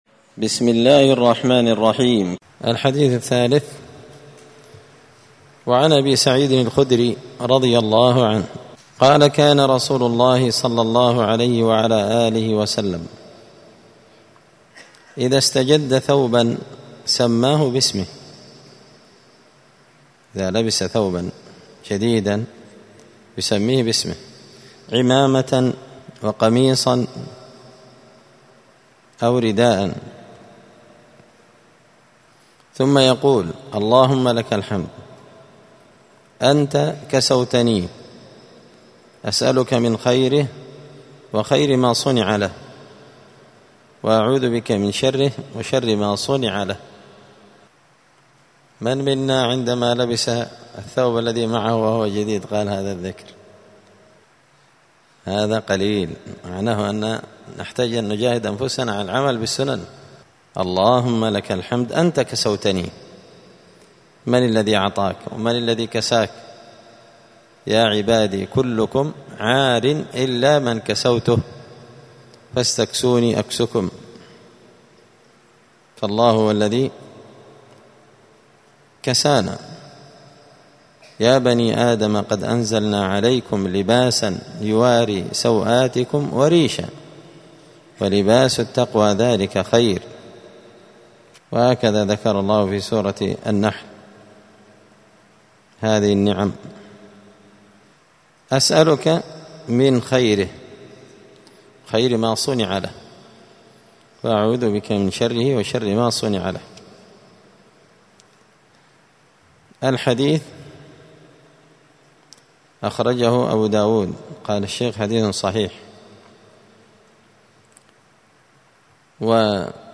*{الدرس الثالث (3) الذكر عند إرادة دخول الخلاء وقبل البدء في الوضوء}*